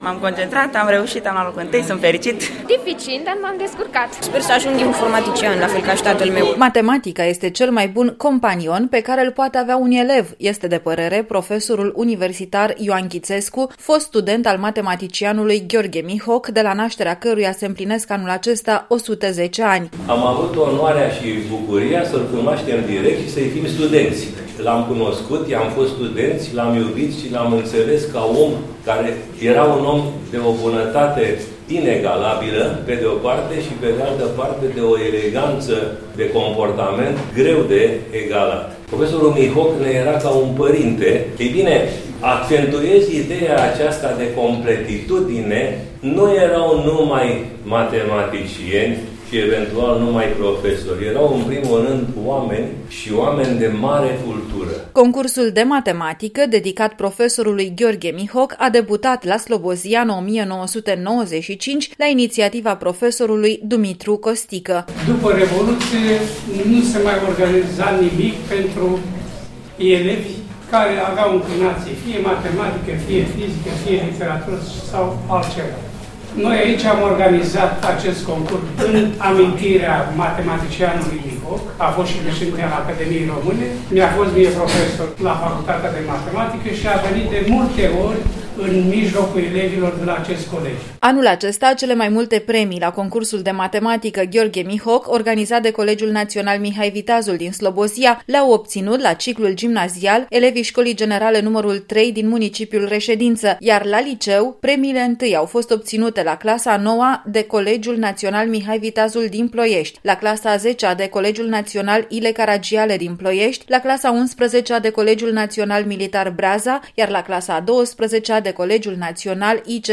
reportaj_concurs_gh_mihoc.mp3